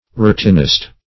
Routinist \Rou*tin"ist\, n. One who habituated to a routine.